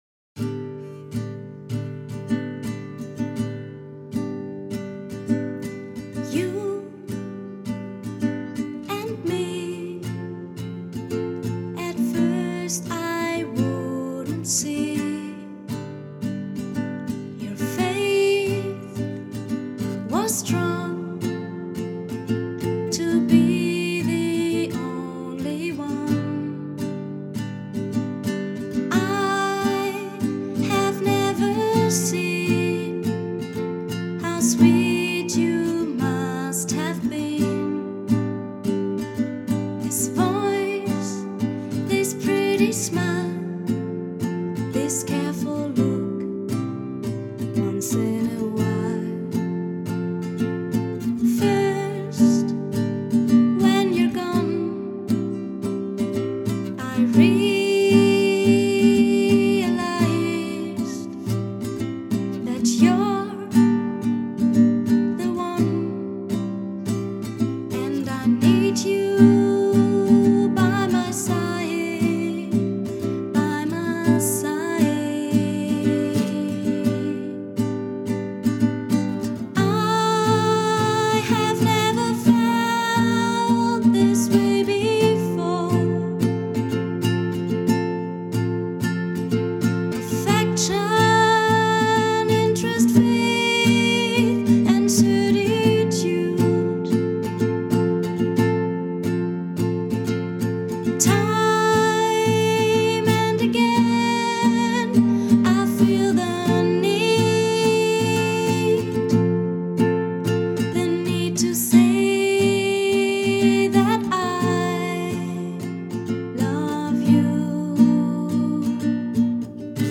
new sound of discomusic and space